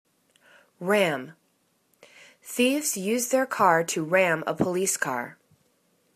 ram      /ram/    v